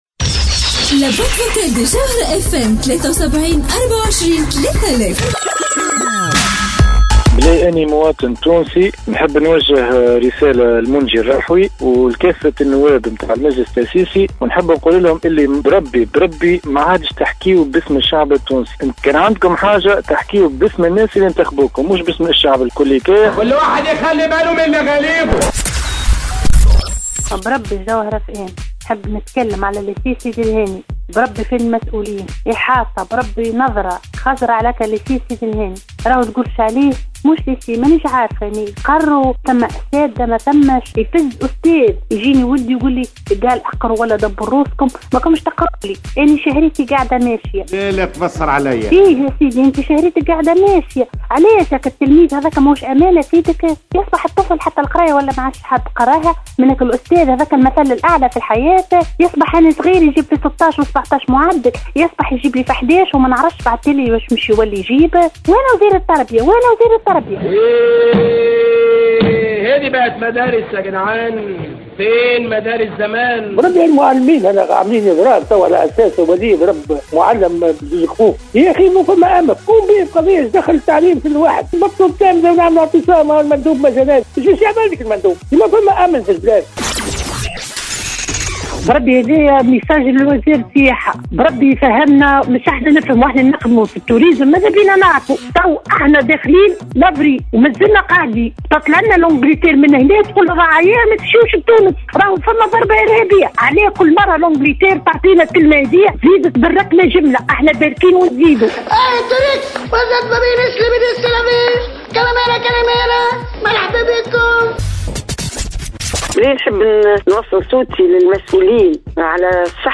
Un auditeur aux politiciens: Vous ne représentez que les personnes ayant voté pour vous...